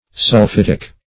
Sul*phit"ic, a. [Slang]